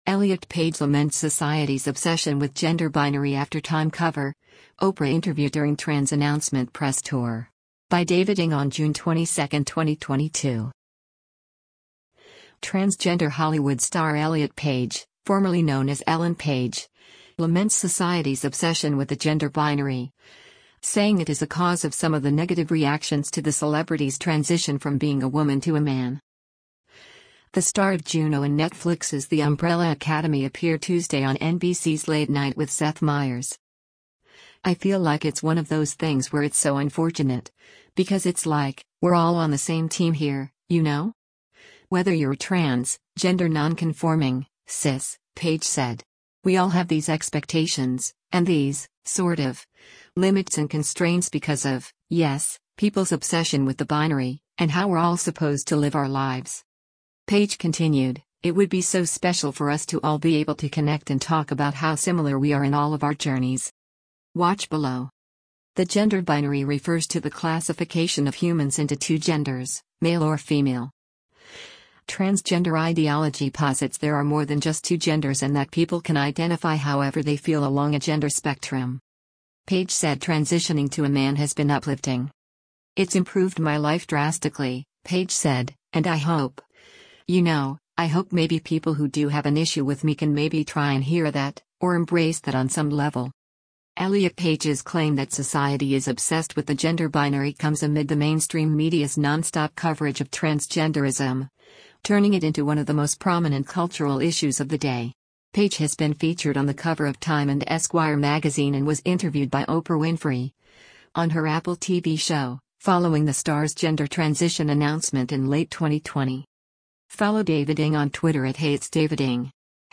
The star of Juno and Netflix’s The Umbrella Academy appeared Tuesday on NBC’s Late Night With Seth Meyers.